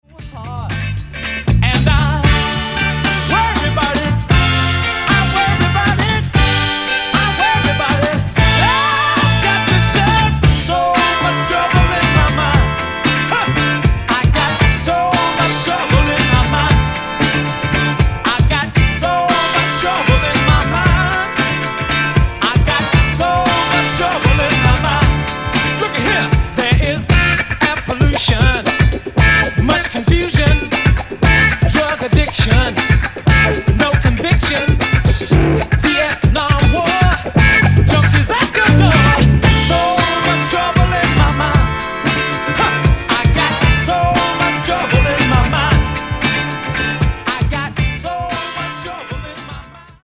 Funky, Afro, Soul Groove e JazzFunk Anni 70 e 80.